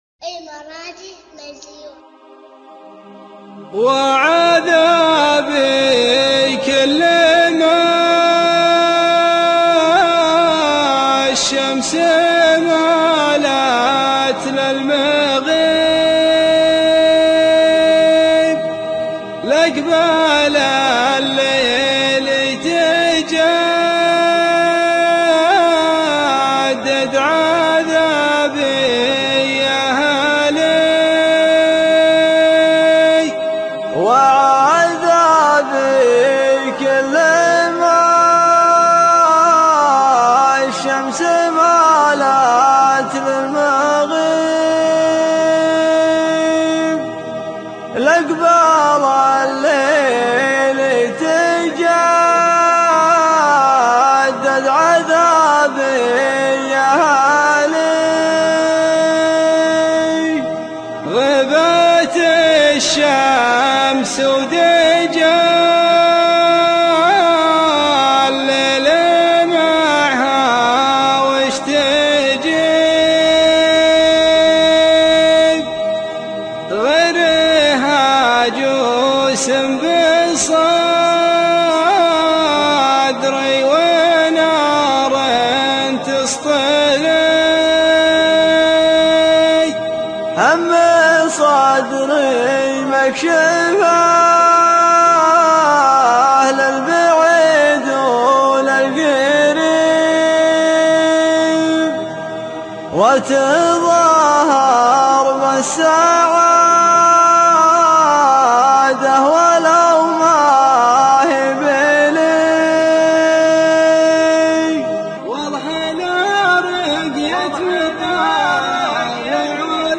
مسرع